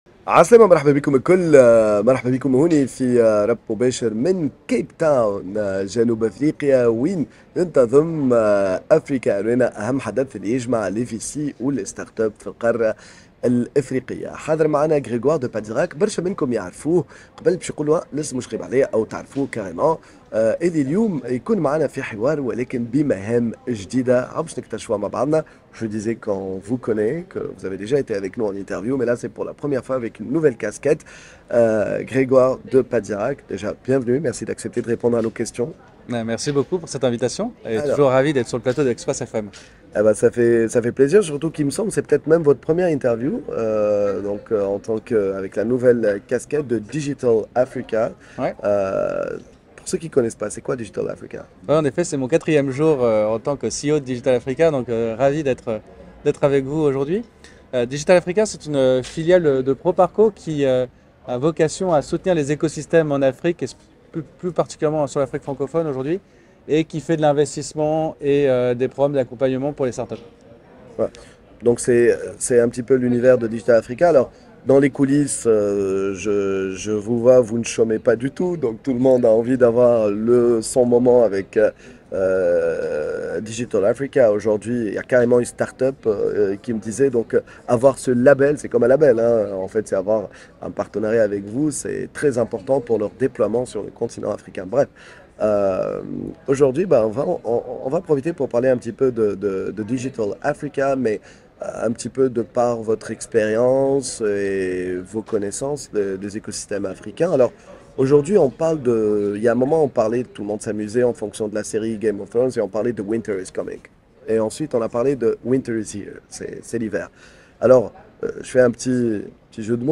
en direct de l'un des plus importants événements d'investisseurs en startups Africarena à Capetown : une interview exclusive